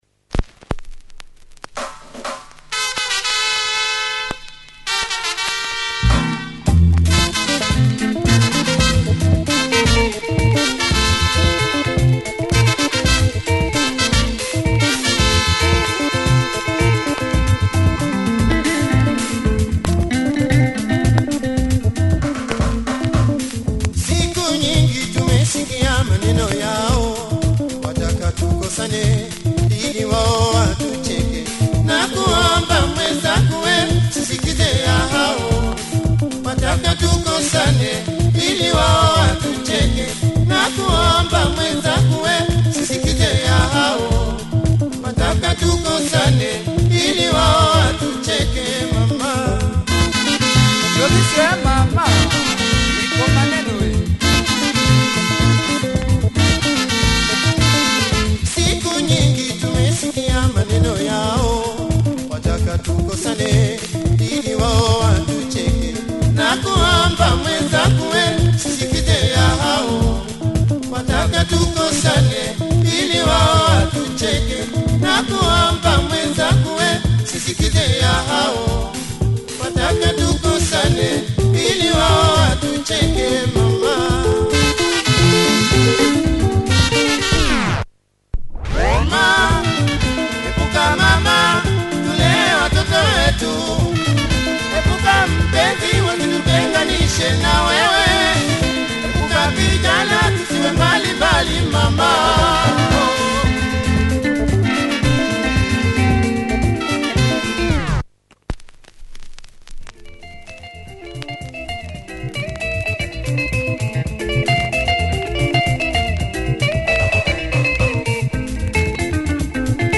Great party soukous